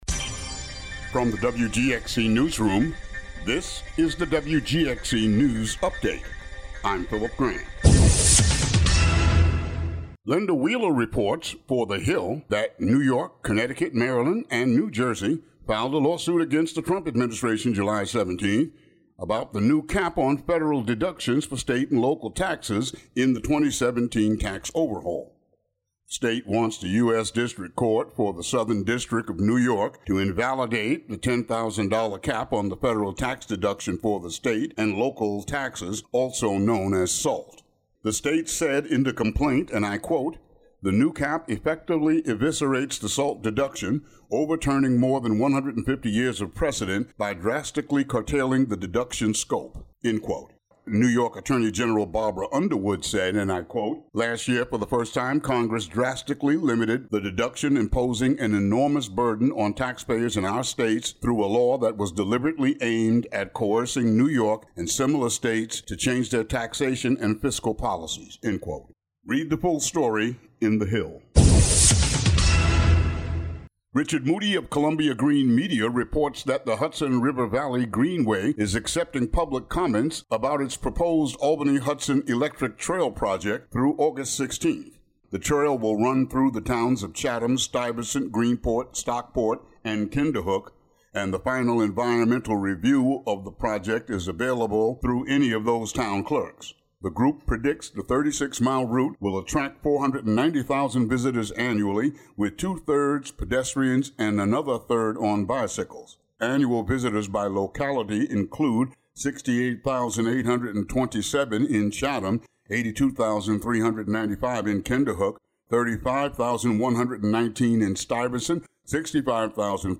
WGXC Local News Update Audio Link
Daily headlines for WGXC.